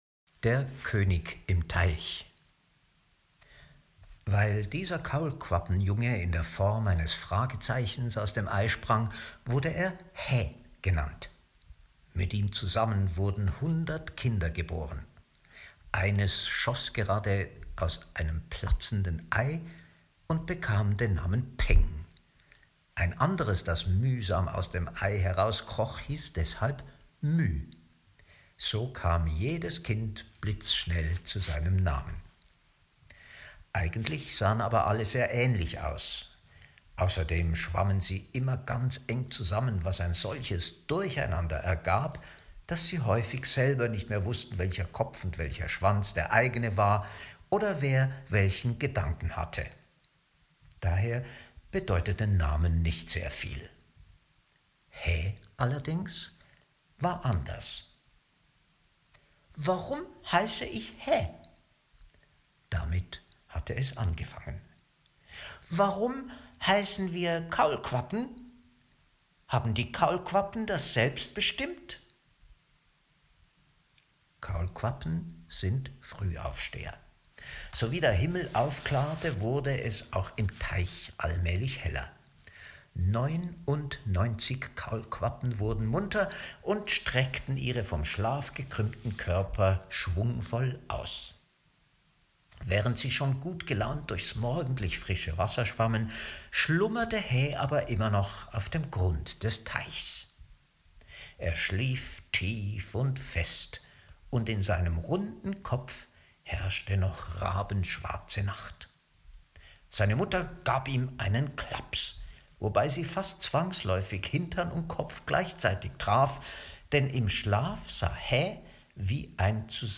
→ Mehr zum BuchWeitere Materialien zum Buch→ Lesung Deutsch (MP3)